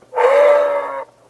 wildlife_moose.ogg